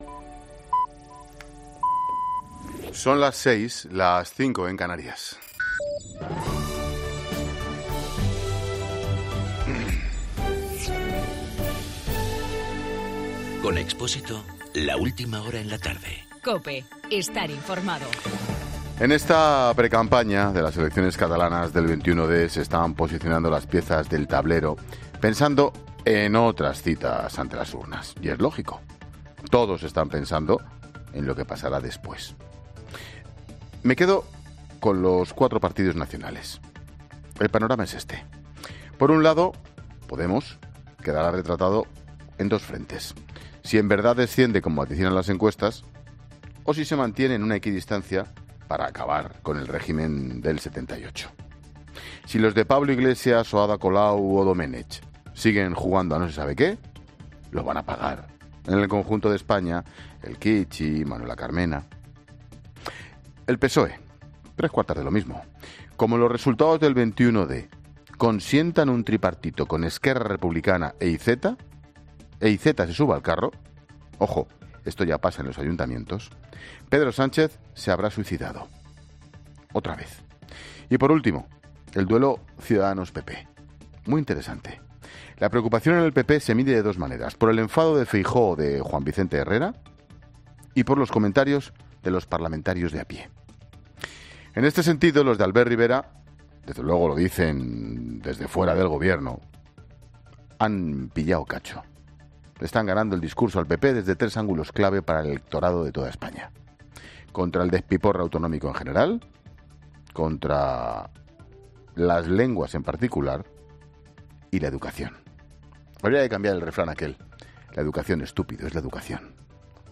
AUDIO: Ángel Expósito analiza en su monólogo de las 18 horas el discurso de los partidos polícitos en plena crisis catalana.